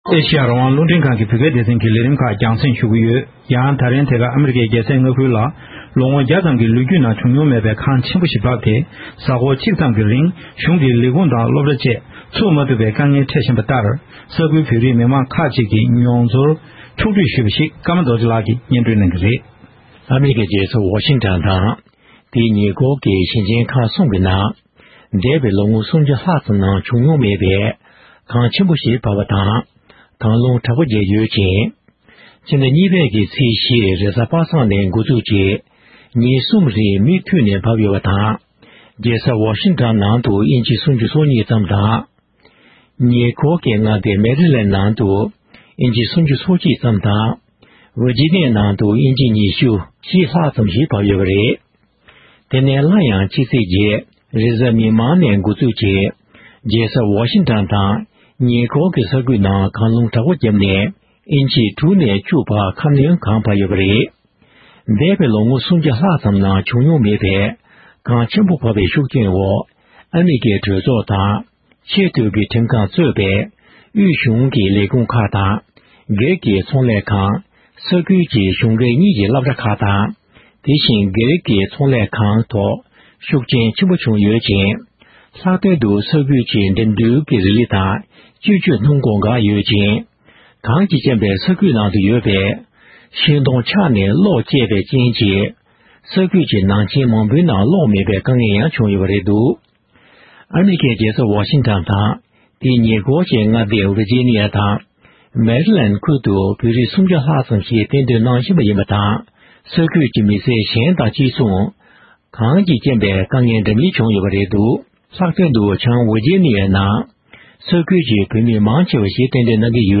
འབྲེལ་ཡོད་བོད་མི་ཁག་ཅིག་ལ་གནས་འདྲི་ཞུས་པར་གསན་རོགས༎